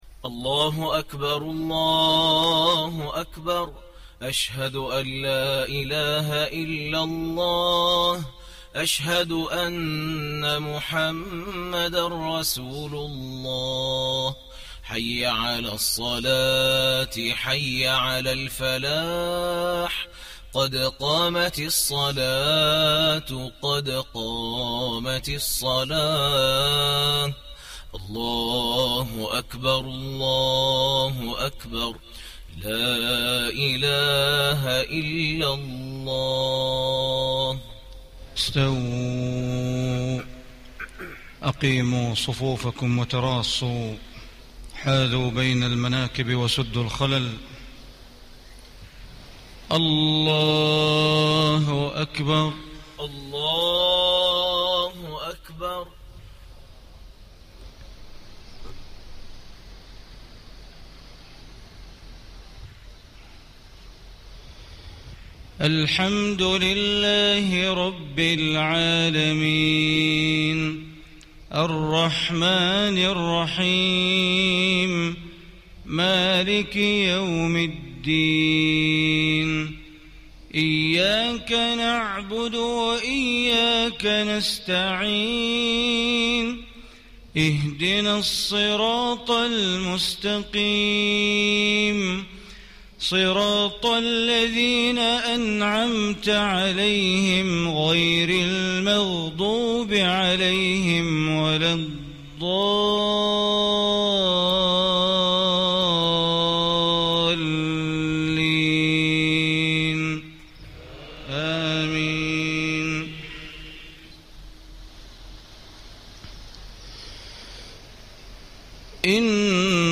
صلاة المغرب 1-6-1436هـ من سورة النبأ > 1436 🕋 > الفروض - تلاوات الحرمين